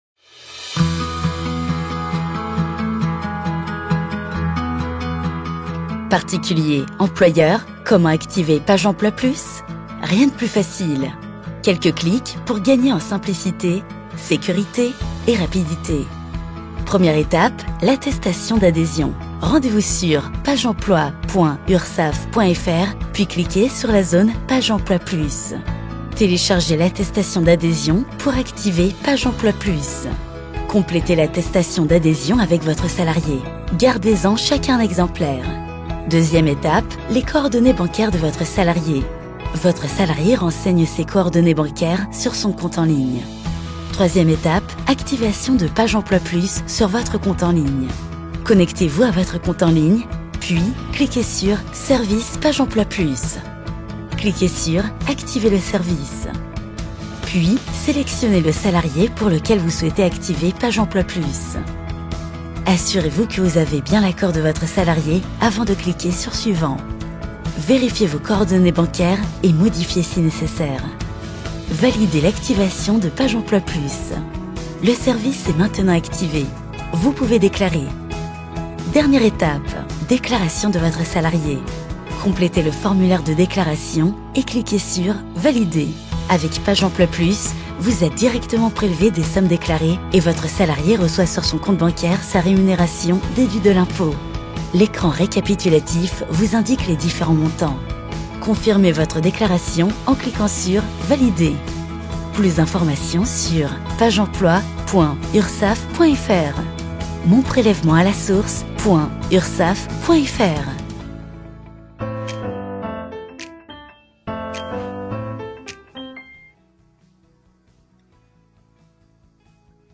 Distinctive, Playful, Friendly, Corporate
E-learning